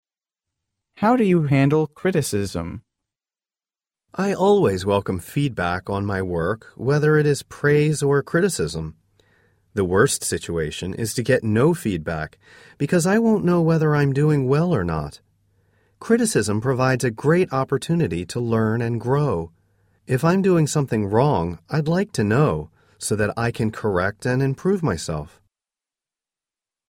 真人朗读，帮助面试者迅速有效优化面试英语所需知识，提高口语能力。